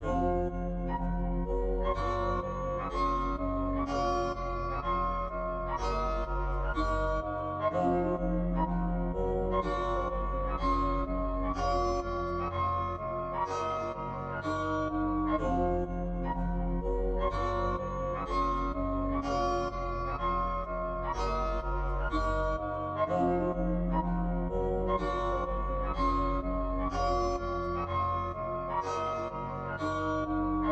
EN - Rodeo (125 BPM).wav